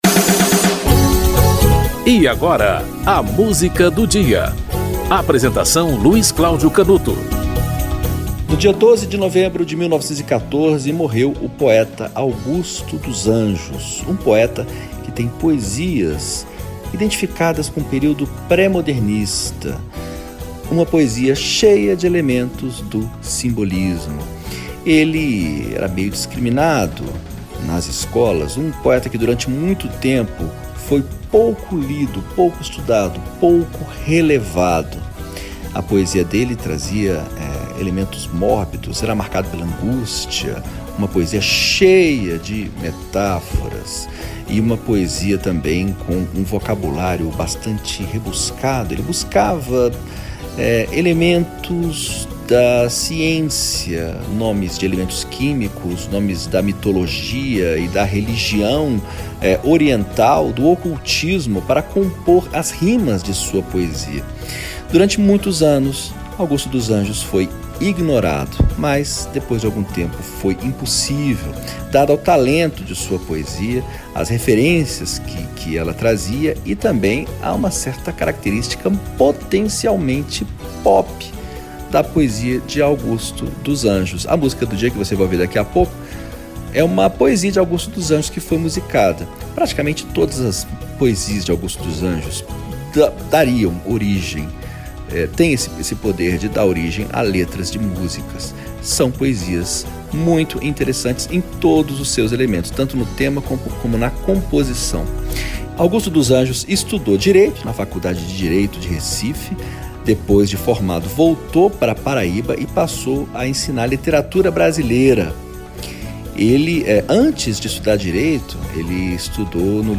Arnaldo Antunes - Budismo Moderno (Arnaldo Antunes e Augusto dos Anjos)
O programa apresenta, diariamente, uma música para "ilustrar" um fato histórico ou curioso que ocorreu naquele dia ao longo da História.